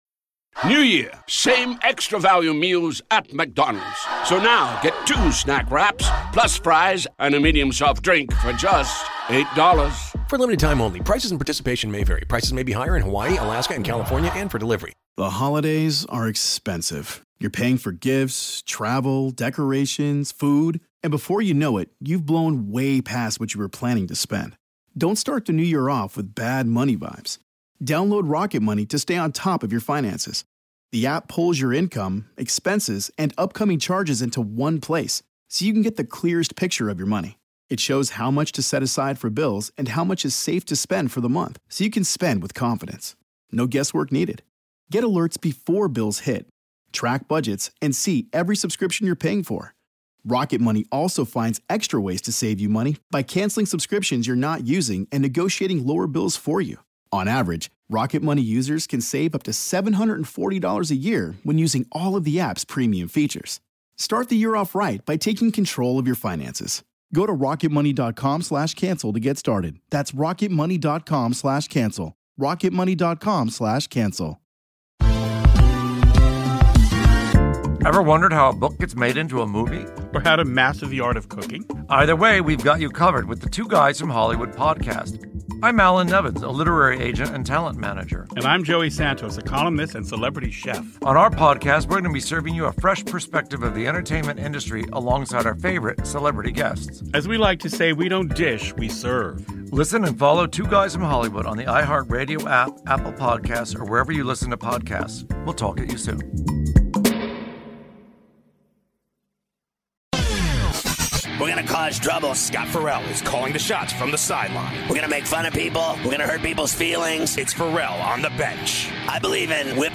Scott Ferrall talks about the NBA and NHL Playoffs from Tuesday and interviews KOA in Denver Host/Broncos Radio PBP Dave Logan about his incredible career as well and the Nuggets/Avalanche/Rockies/Broncos